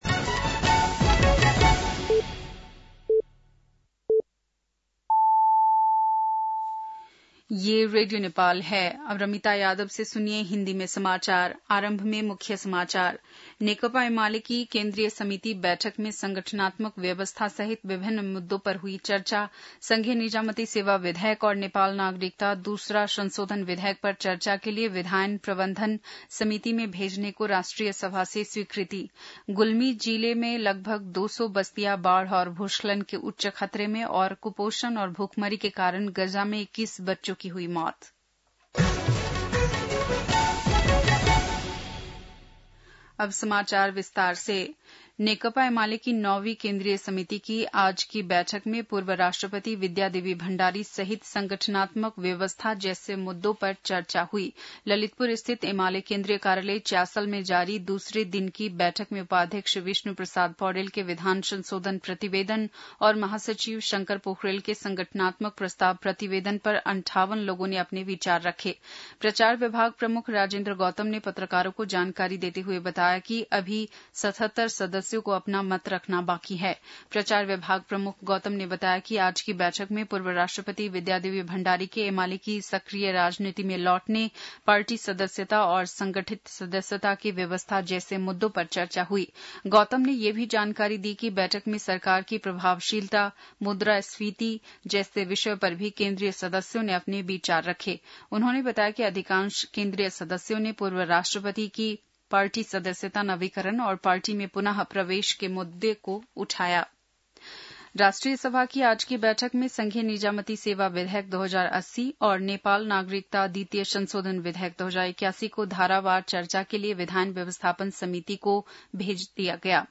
बेलुकी १० बजेको हिन्दी समाचार : ६ साउन , २०८२
10-pm-hindi-news-4-06.mp3